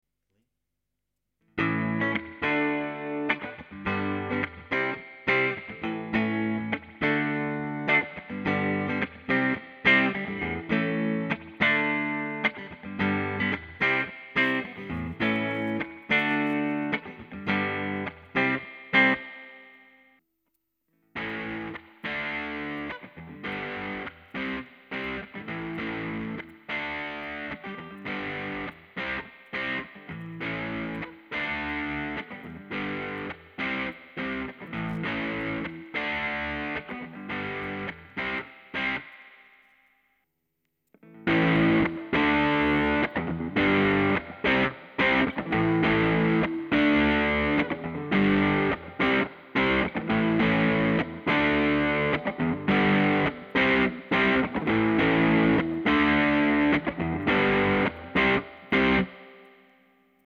Recorded with a Fender 62 Relic Strat middle pick up, Peavey Classic 50 tube amp on clean channel.
Take 1 Clean Fender then stock Marshall Bluesbreaker II then modded Marshall Bluesbreaker II
dextermods_marshall_bluesbreaker.mp3